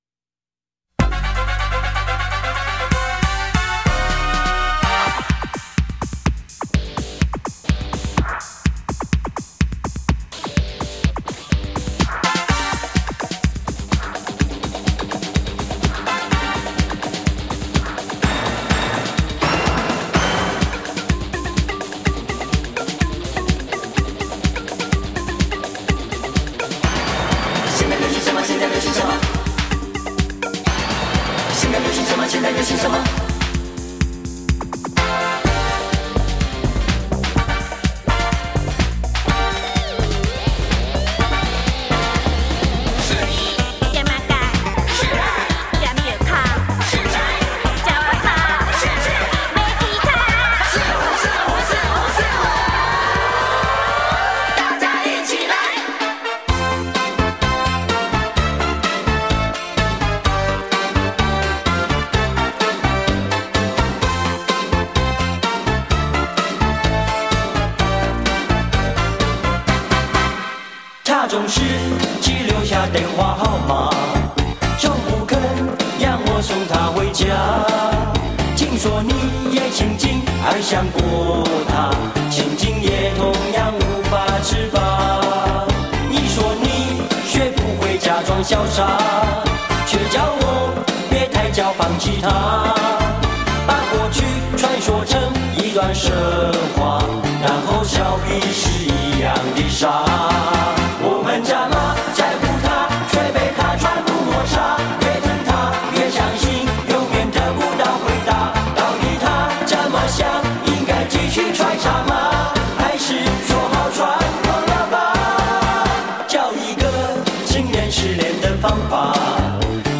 最多的流行的歌曲
45就是 采45转快转的方式演唱串联当红歌曲的组曲 记录着70.80年代台湾流行乐史